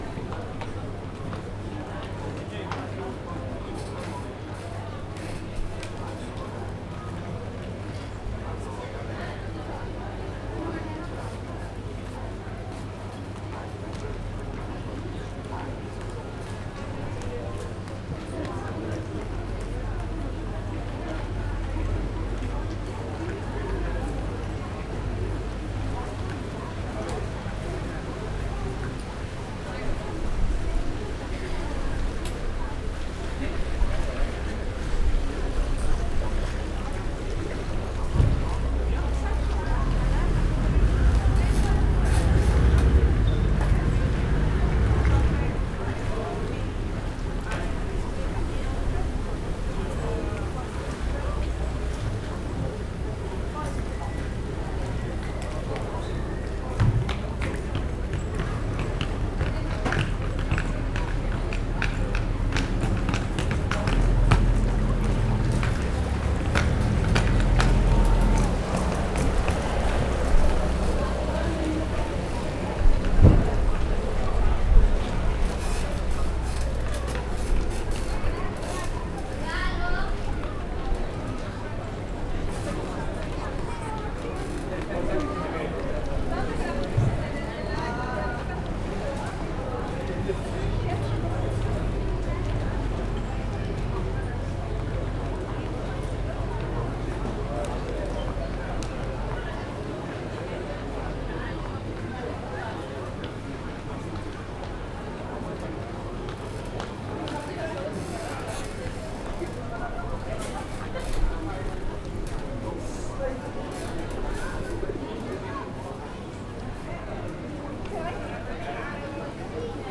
balraesszembenvendeglok_jobbrahid_velence_sds03.14.WAV